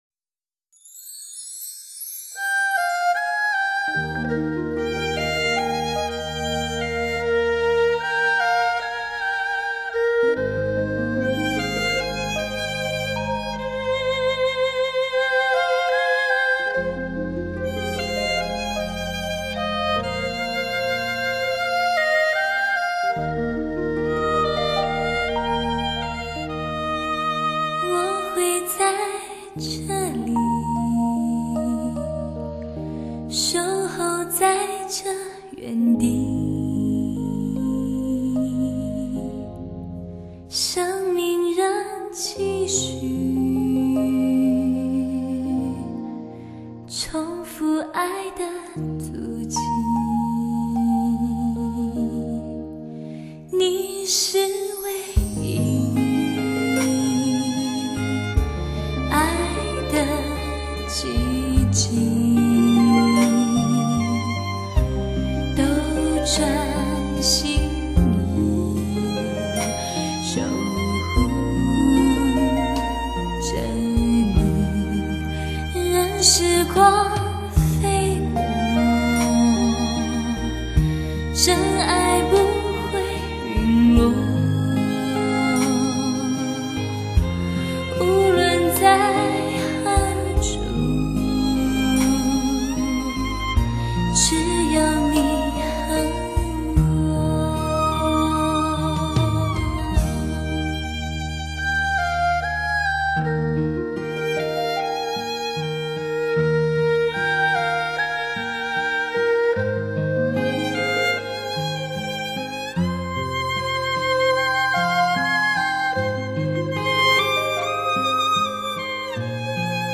这里收录了最受欢迎的韩剧主题曲完整版，韩国歌手的歌声甜美入心，将歌词的内容演绎至情至圣、 唯美唯善。